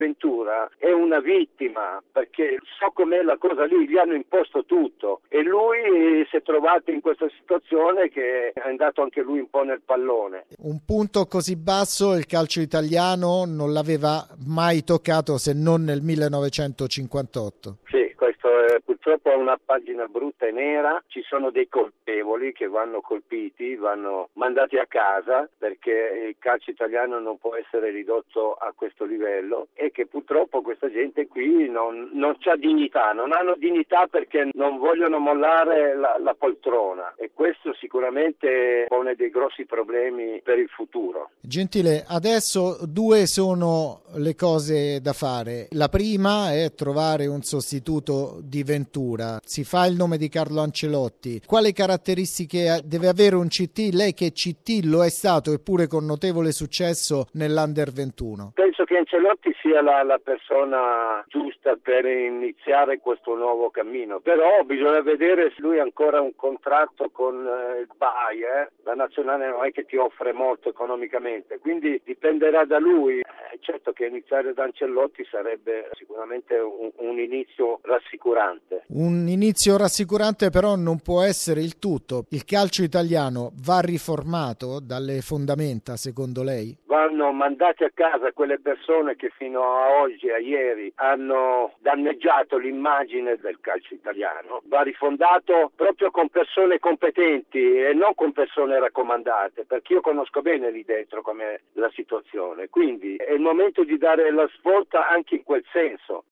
Claudio Gentile a 'Zona Cesarini'-intv